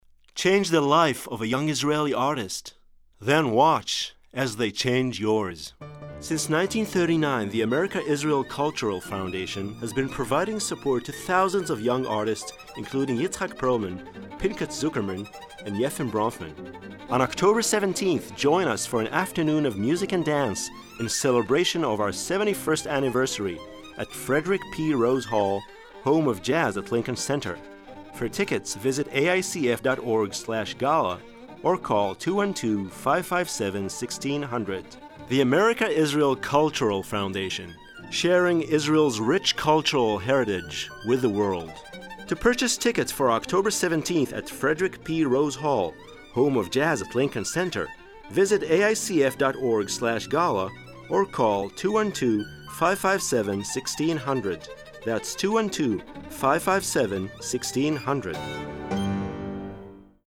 English and Hebrew speaker, Baritone, Narration, Commercials, Character work, NYC voice overs
Sprechprobe: Sonstiges (Muttersprache):